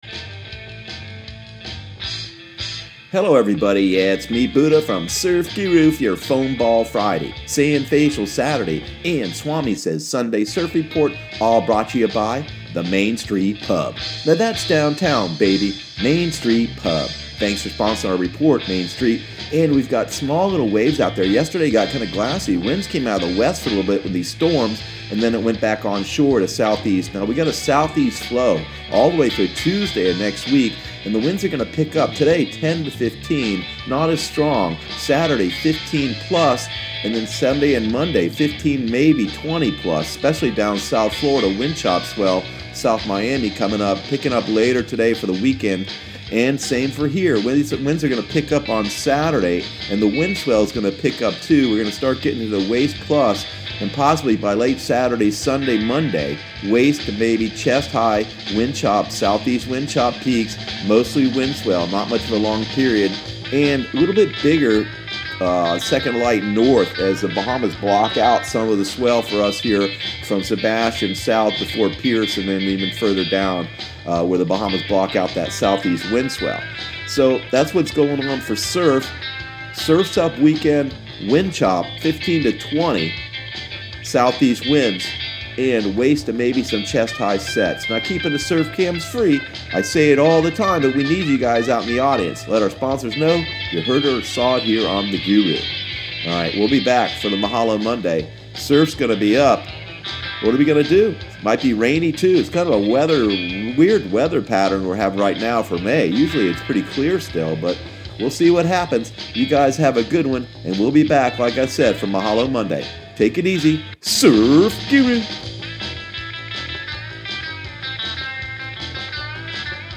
Surf Guru Surf Report and Forecast 05/18/2018 Audio surf report and surf forecast on May 18 for Central Florida and the Southeast.